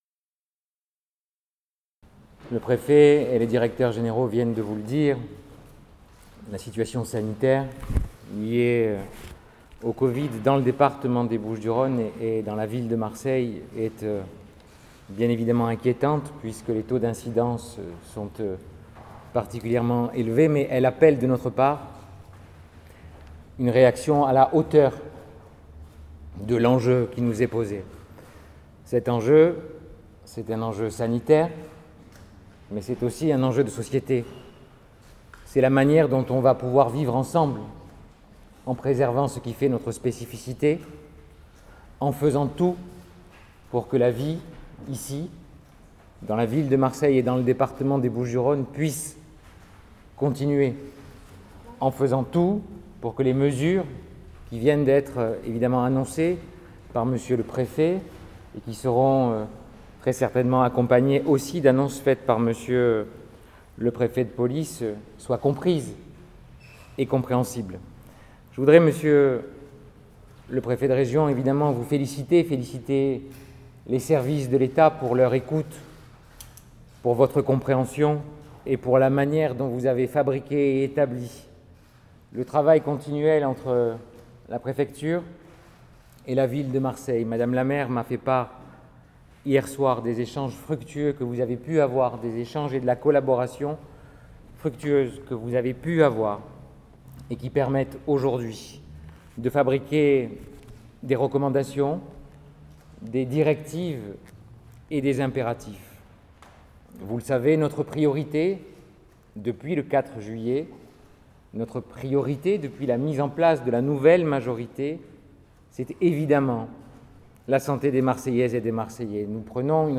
Seul élu appelé à prendre la parole -lors de la conférence de presse du préfet sur la situation sanitaire dans les Bouches-du-Rhône et tout particulièrement à Marseille et les mesures que cela impose- il a indiqué que, dans cette lutte contre l’épidémie de la Covid-19, la Ville ferait tout ce qui est en son pouvoir et va prendre plus que sa charge mais qu’elle ne pourrait pas tout faire et en appelle en premier chef à L’État car «la Ville ne pourra assumer seule l’immense défi sanitaire».
son_copie_petit-434.jpg Intervention de Benoît Payan